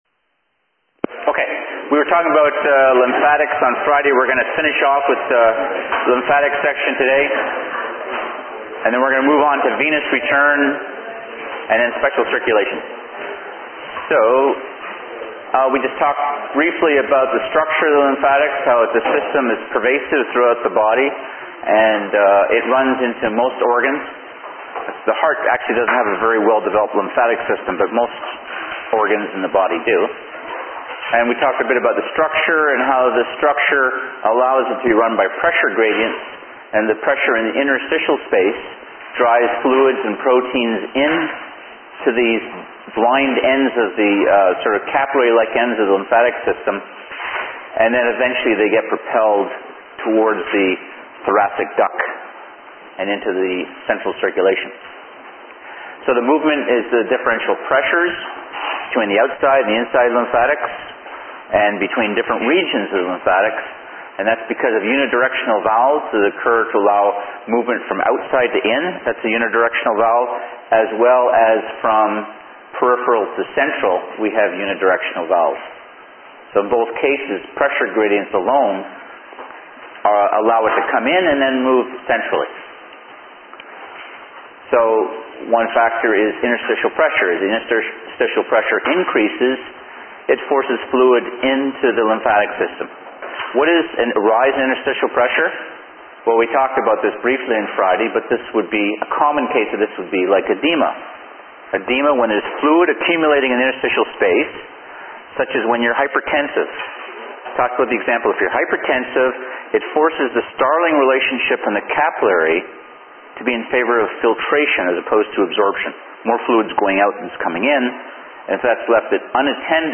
Digital recording of lecture MP3 file requires an MP3 player